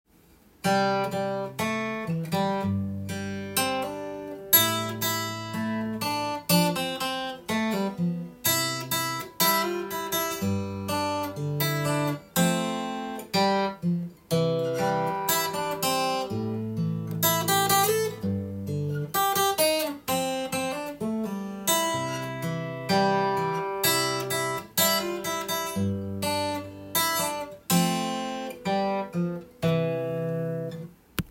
アコースティックギターで癒しの曲を弾きたいという
譜面通り弾いてみました
tab譜は主に、8分音符や１６分音符がメインになりますが
付点１６分音符がありタメの演奏が必要になります。